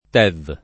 tev [ t $ v ]